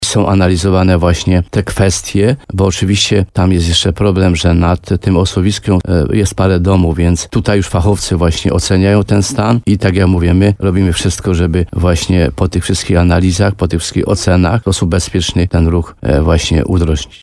– Po konsultacjach z nami, Generalna Dyrekcja Dróg Krajowych i Autostrad sprawdza, czy wprowadzenie ruchu wahadłowego będzie bezpieczne – mówił w programie Słowo za Słowo na antenie RDN Nowy Sącz starosta limanowski, Mieczysław Uryga.